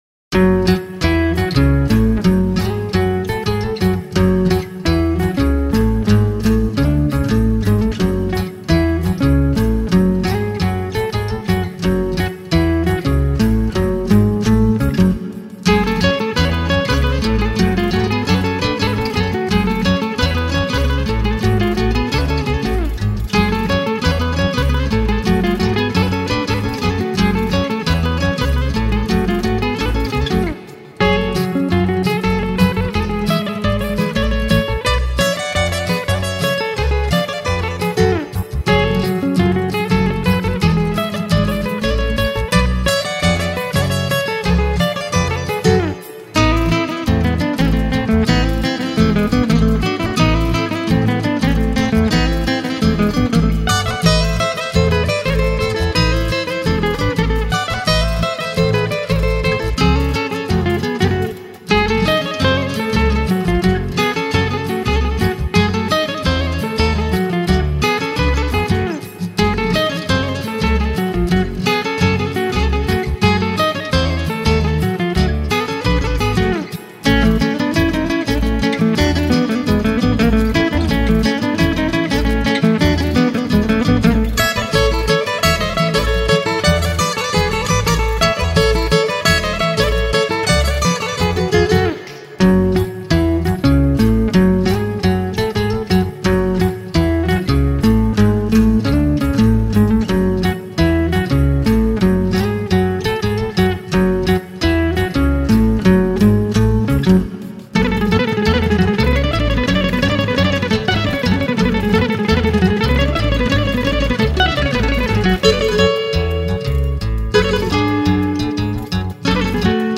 10 instrumentala